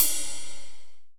D2 RIDE-07.wav